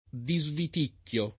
disviticchiare
disviticchio [ di @ vit & kk L o ]